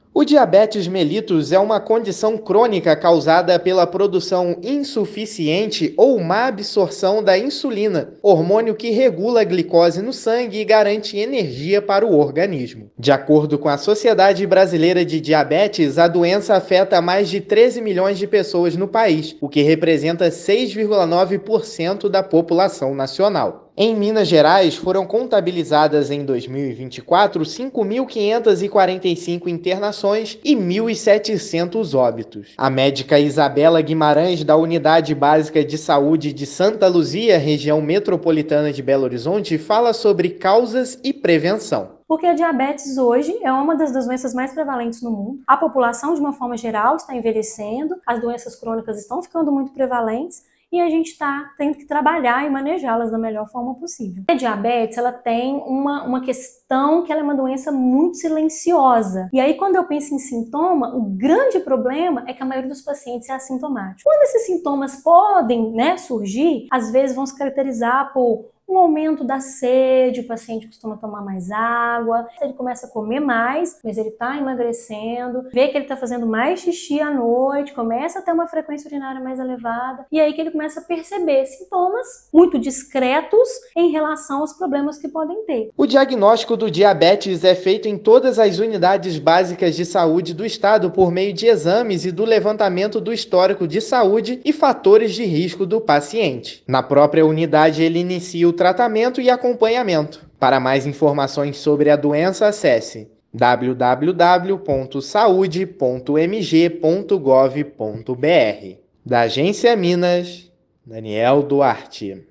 [RÁDIO] Secretaria de Saúde alerta para os riscos do diabetes
Doença crônica pode ser prevenida com a adoção de hábitos saudáveis; diagnóstico e tratamento estão disponíveis em todas as unidades básicas de saúde do estado. Ouça a matéria de rádio: